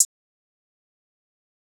Hihat 9 Metro.wav